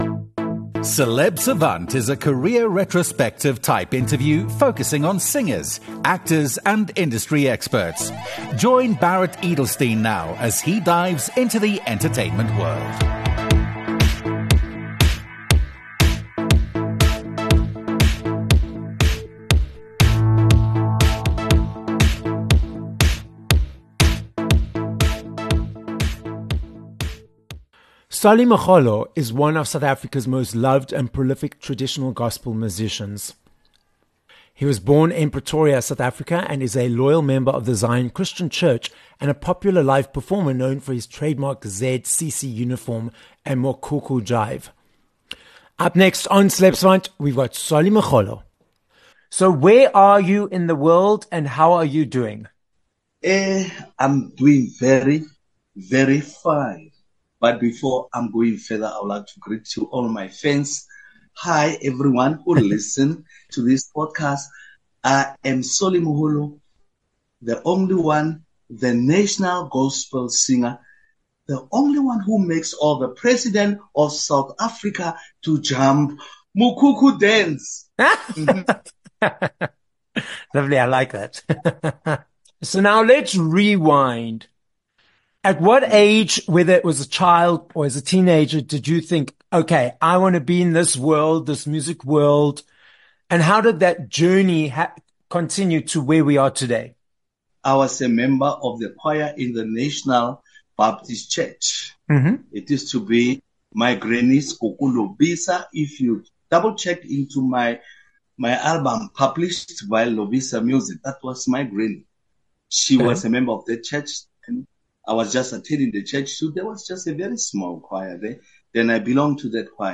9 Jun Interview with Solly Moholo
Known to get you to do the ‘Mokhukhu jive’ - South African gospel singer, Solly Moholo is the guest on this episode of Celeb Savant. Solly details his multi-decade success as part of, and producing, various choirs… and tells us about his latest single, ‘Stop Killing The Police’.